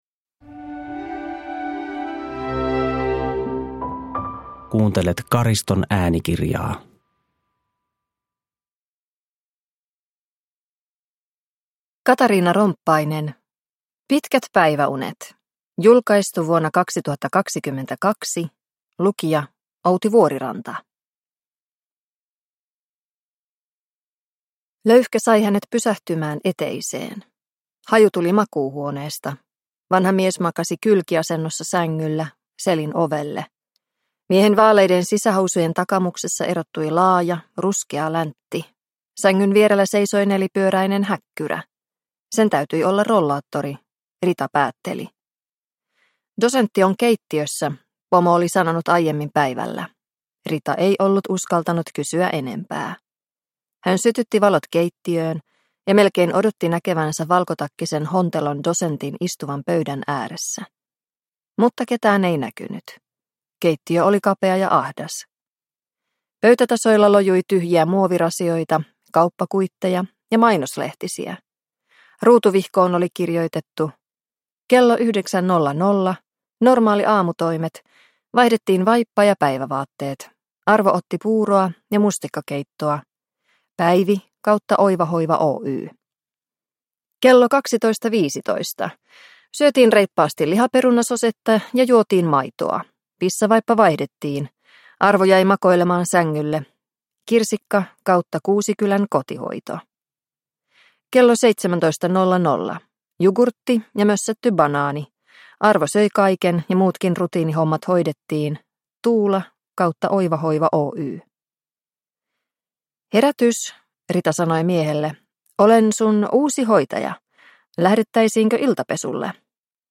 Pitkät päiväunet – Ljudbok – Laddas ner